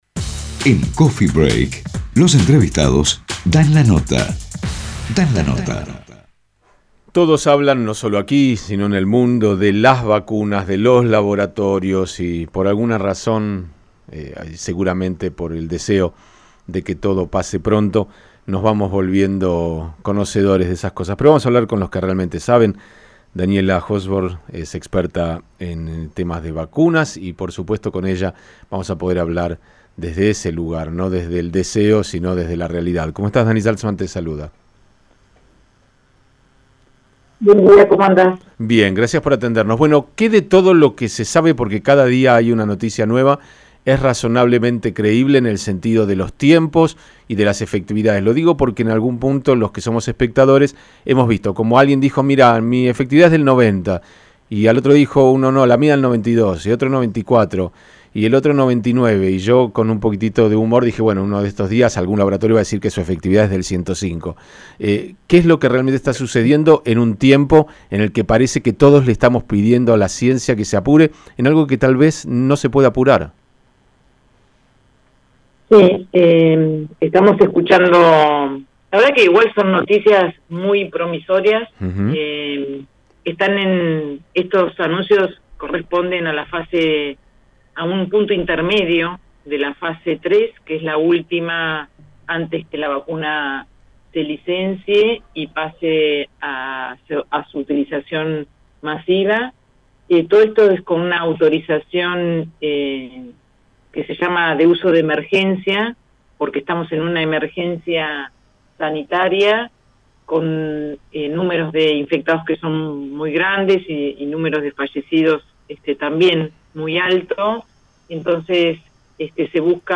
experta en vacunas